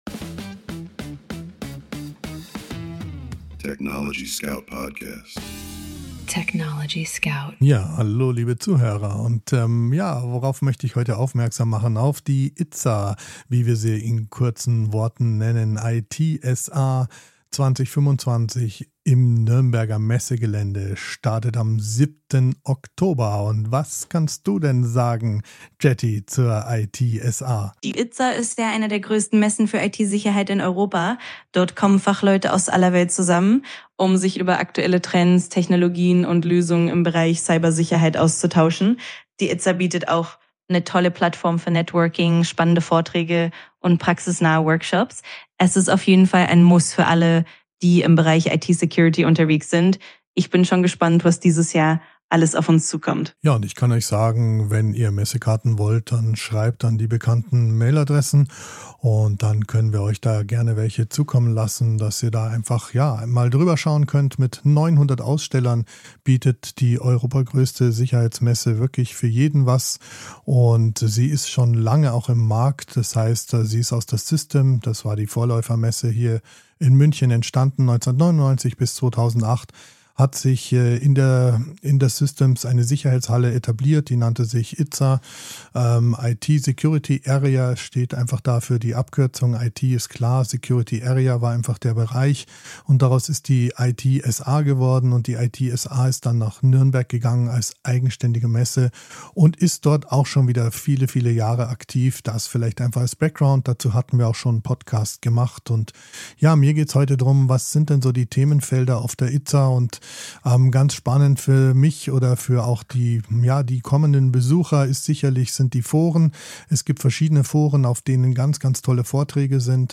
"Live aus dem Studio des TechnologieScout